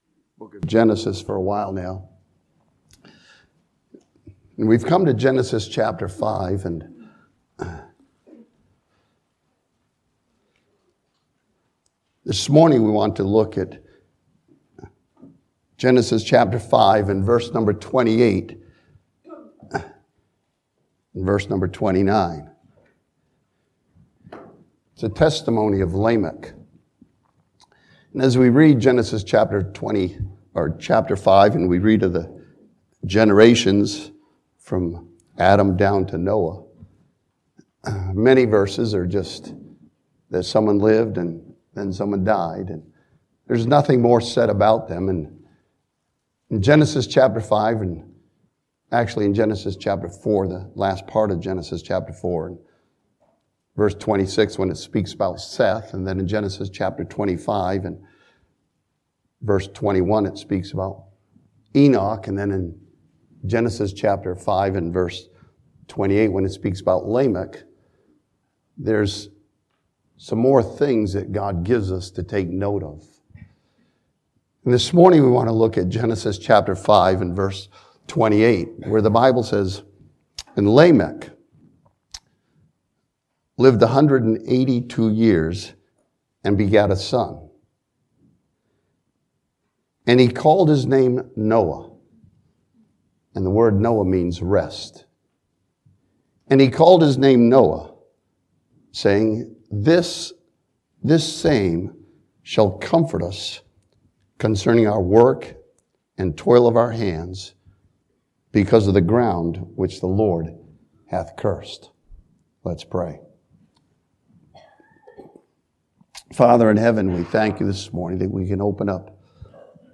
This sermon from Genesis chapter 5 studies Lamech, his son Noah, and the promise of a person.